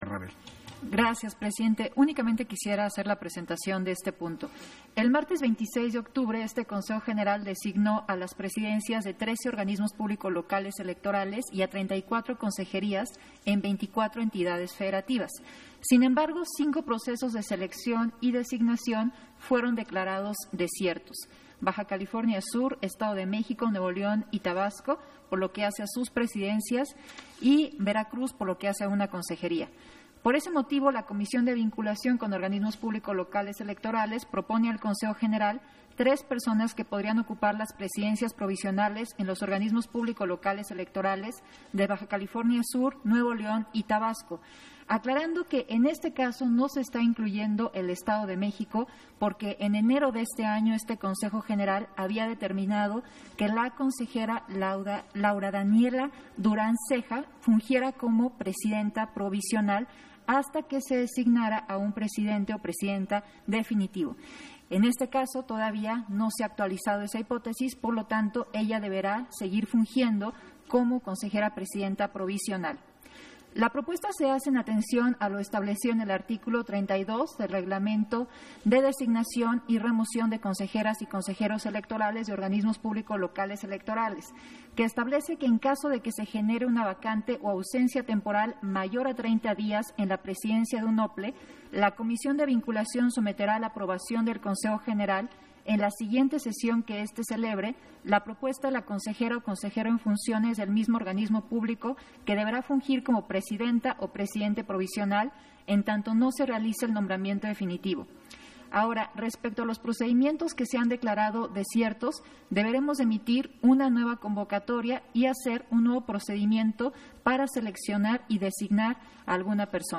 Intervención de Dania Ravel, en Sesión Extraordinaria, en el punto en que se aprueba la designación de presidencias provisionales en Organismos Públicos Locales